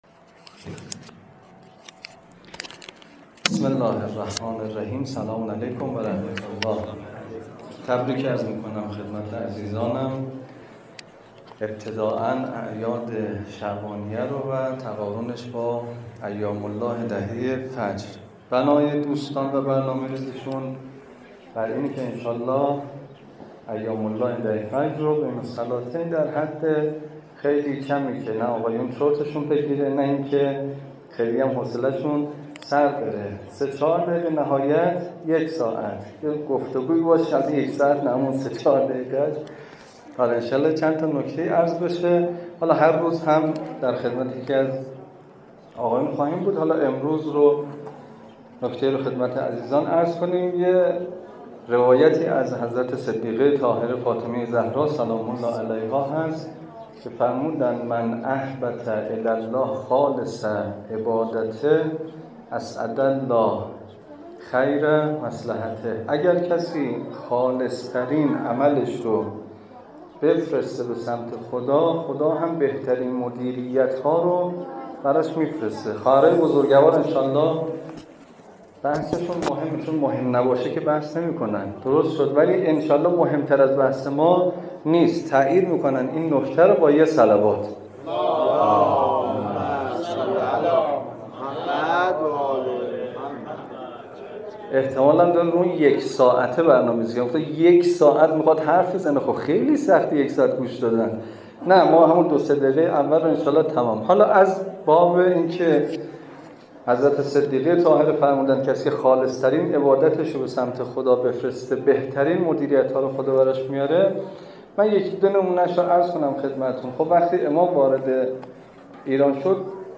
در پایان، سخنران به فضایل دهه فجر و اهمیت این ایام اشاره کردند و از حضار خواستند که با الگوگیری از امام خمینی (ره) و با اخلاص در اعمال، در مسیر تحقق آرمان‌های انقلاب اسلامی گام بردارند.